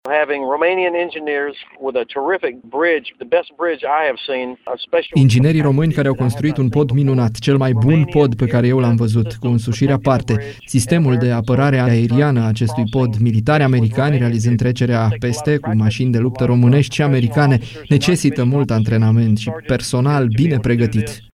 Comandatul Forţelor Militare Americane din Europa, Generalul Ben Hudges, spune că inginerii români au reușit să construiască cel mai bun pod pe care l-a văzut.
ben-hudges-USA.mp3